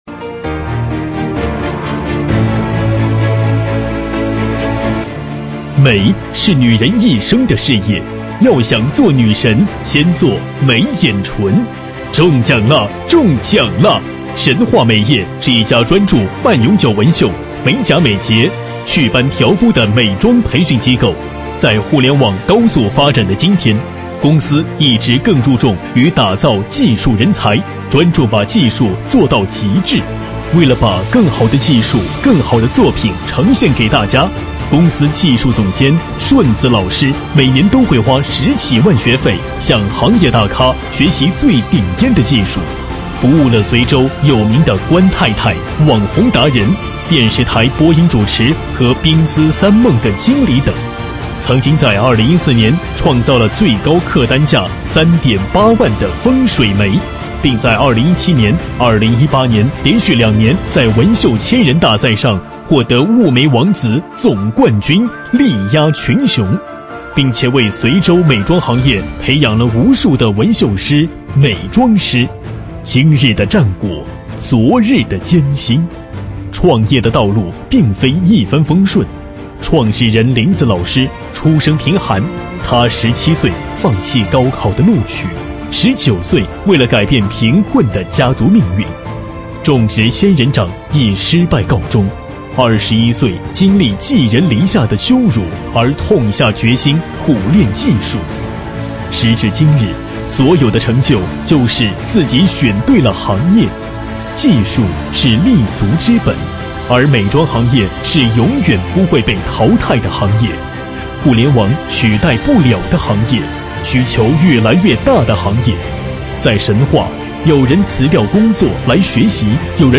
【男14号专题】神话美业公司介绍
【男14号专题】神话美业公司介绍.mp3